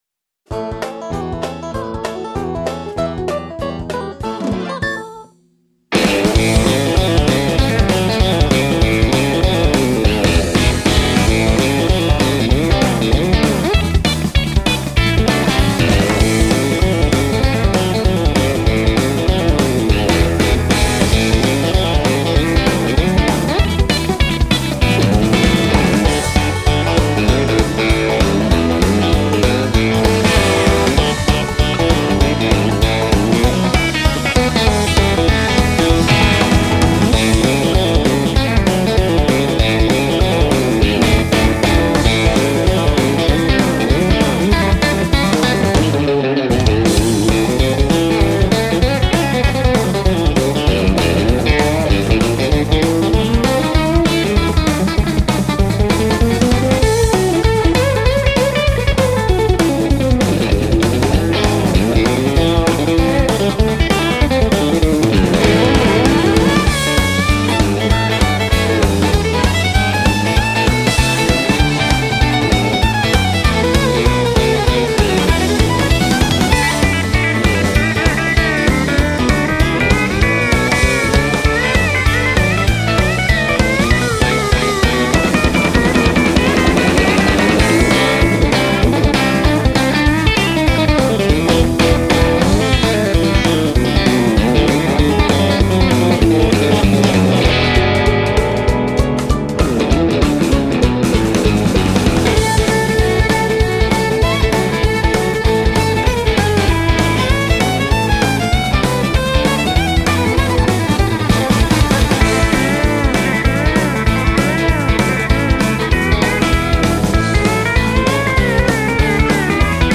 音乐风格: 乡村
是一张以吉他纯演奏为主、明星合唱为辅的专辑
让你享受到一股新鲜的摇滚气味。